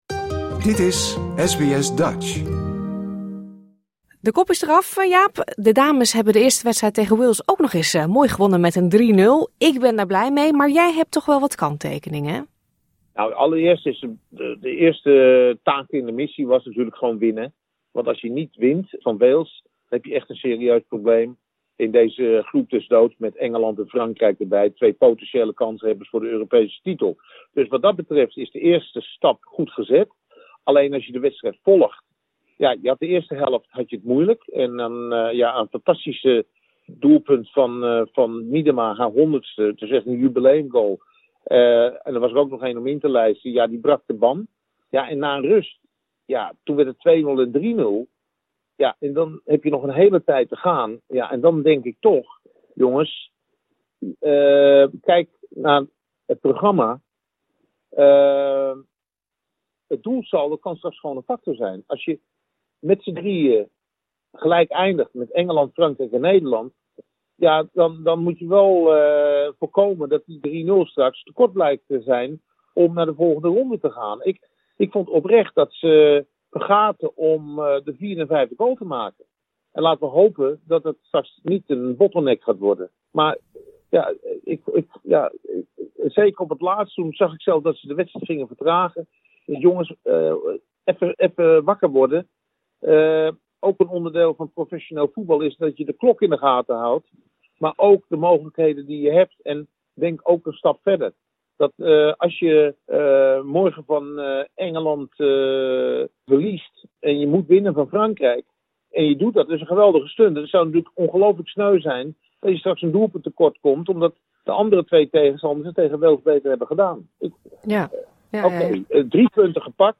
De Oranjeleeuwinnen wonnen afgelopen weekend hun eerste wedstrijd op het EK met 0-3 van Wales. Aankomende nacht speelt Nederland tegen Engeland, de huidig Europees kampioen, die haar eerste groepswedstrijd tegen Frankrijk verloor. We bellen met sportjournalist Jaap de Groot.